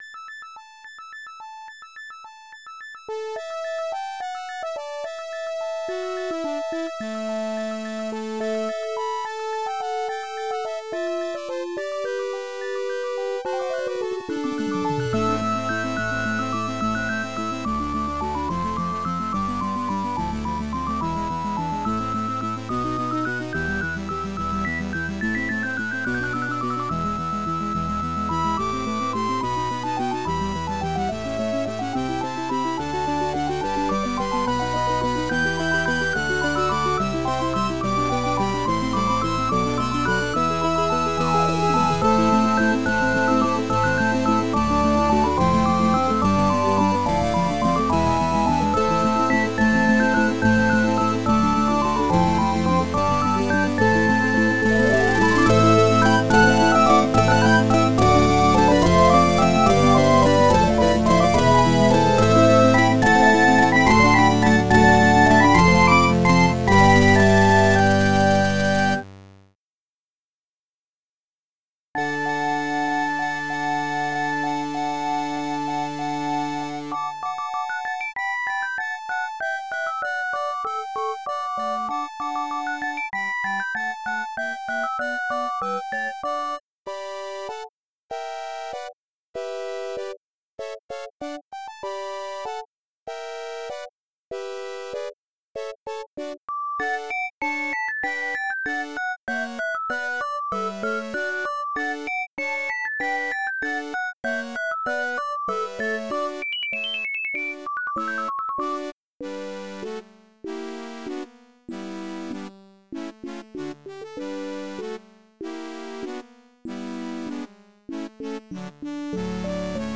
Really didn't know what to title this as it has the hallmarks of being a Pastorale-esque feel to it and originally written for an ensemble group...but I could not resist listening to a chiptuned version as well so ended up creating this.
chiptune classical romantic game music retro 8-bit 16-bit orchestral
pastorale upbeat electronic videogame orchestra
love the dynamics of this! especially around 3:10